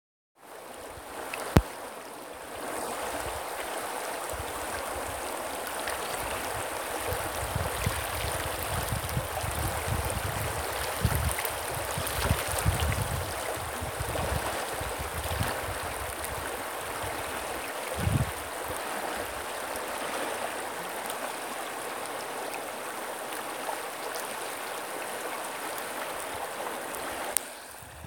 …The sound of rushing water while I was sitting beside the Truckee River in California last summer: